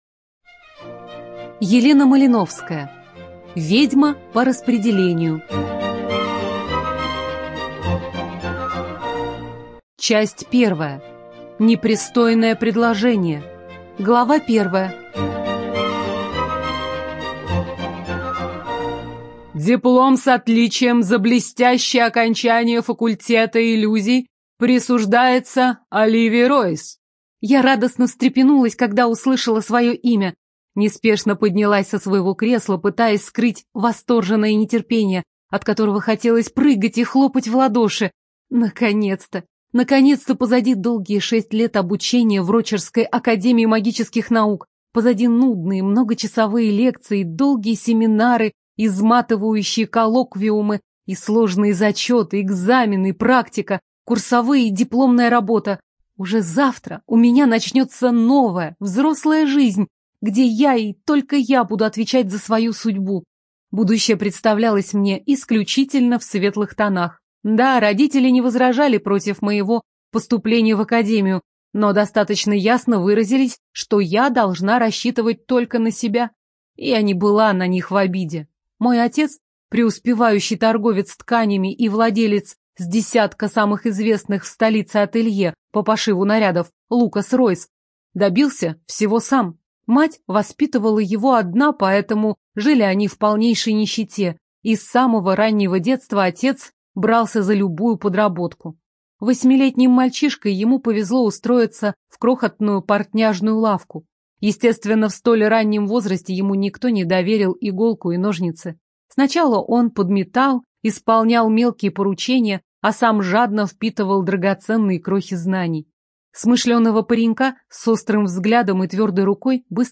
ЖанрФэнтези